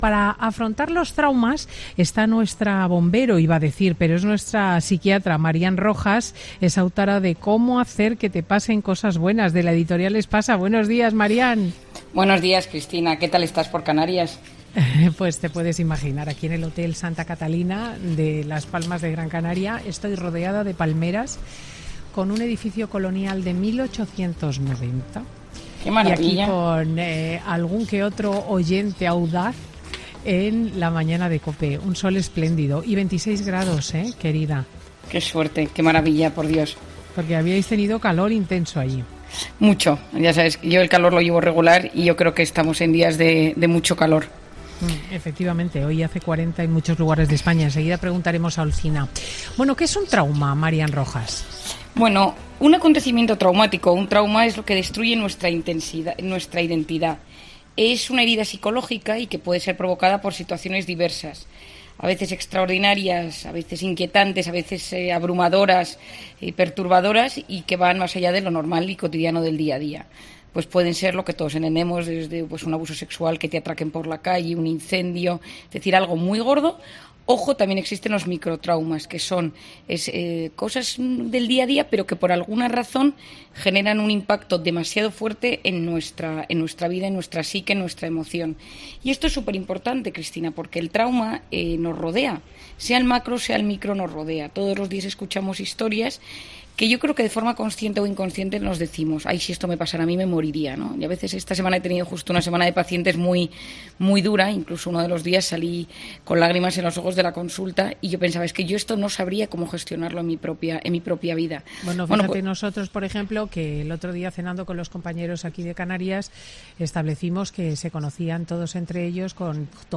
Marian Rojas, psiquiatra y autora de 'Cómo hacer que te pasen cosas buenas', habla en Fin de Semana con Cristina sobre cómo cerrar heridas emocionales